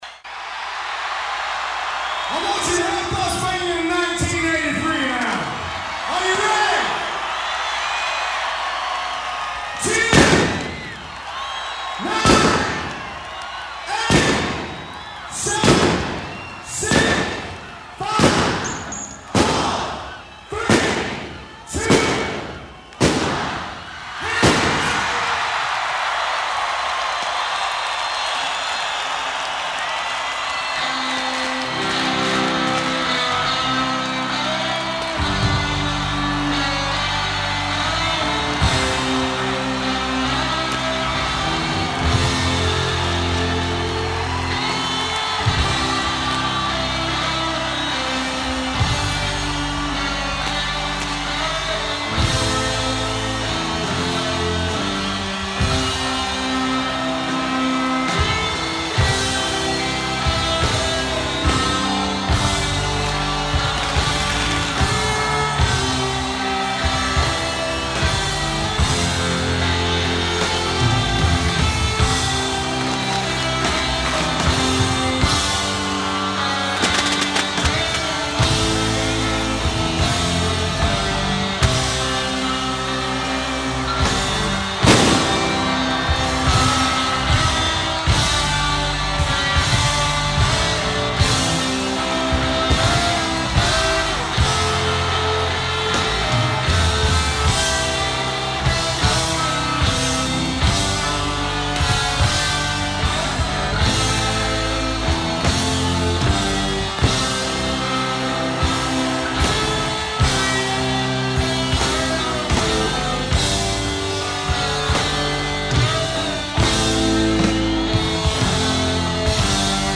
blistering lead guitar